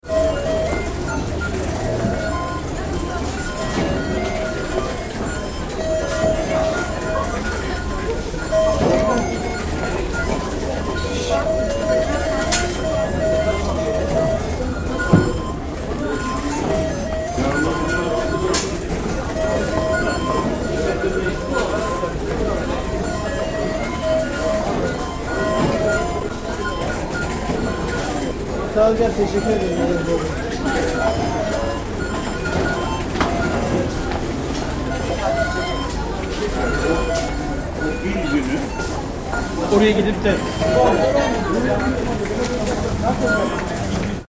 Eminönü ferry pier, 6 pm, Tuesday.
All this akbil powered human traffic turns the piers and stops into places of electric symphonies.